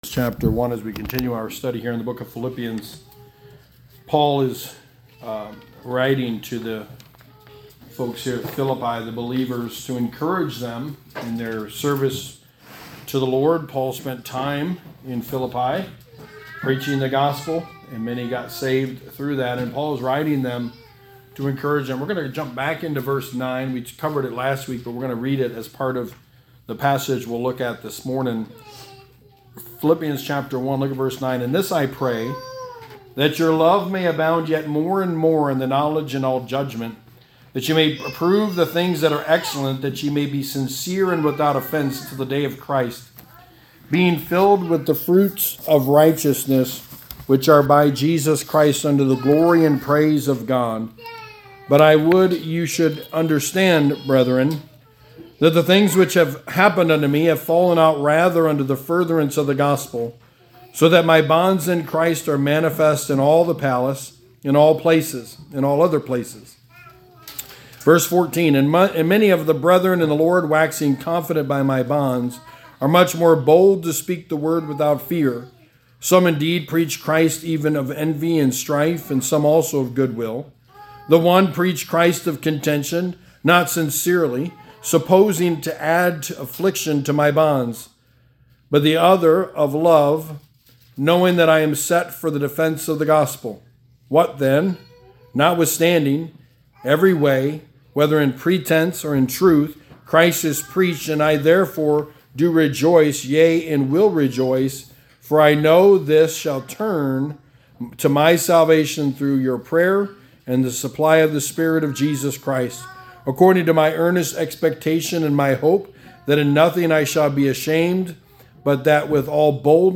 Sermon 6: The Book of Philippians: Boundless Faith
Service Type: Sunday Morning